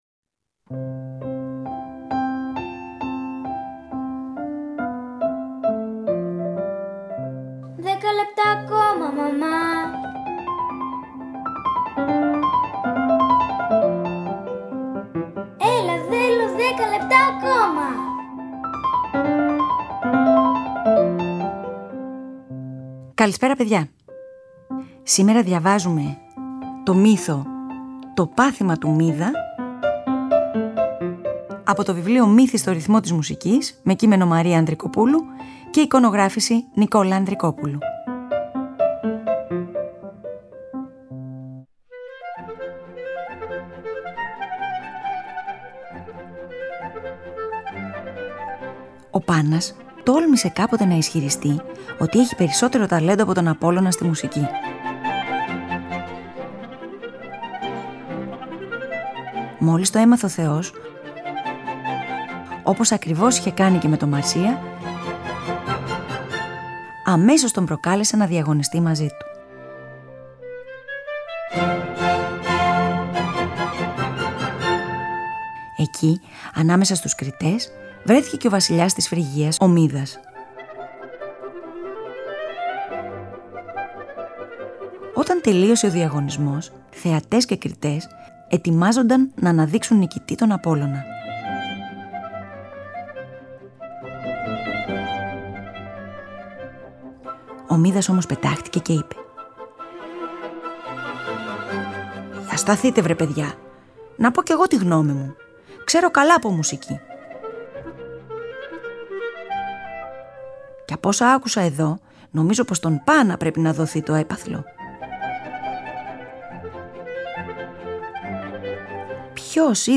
Carl Maria von Weber, Clarinet Concerto No.1 in F Minor, Op.73:3. Allegretto